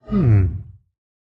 Minecraft Version Minecraft Version snapshot Latest Release | Latest Snapshot snapshot / assets / minecraft / sounds / mob / sniffer / idle7.ogg Compare With Compare With Latest Release | Latest Snapshot